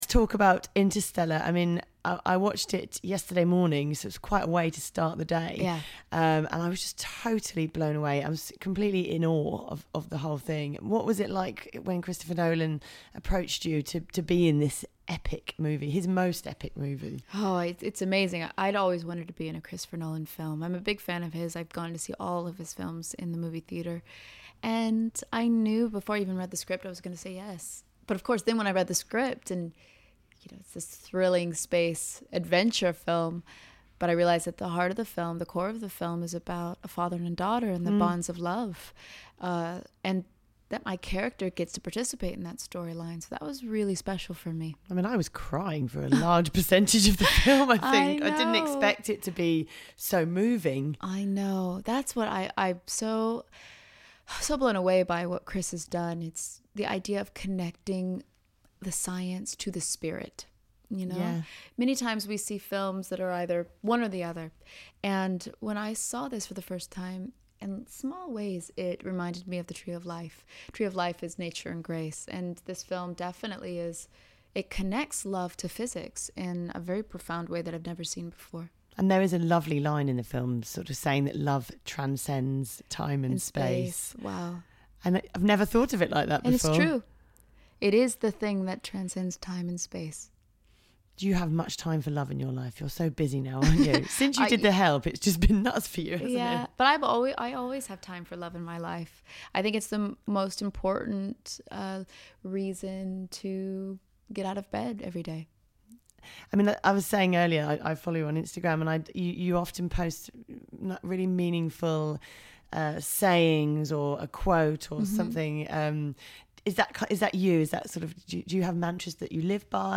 Jessica Chastain Interstellar Interview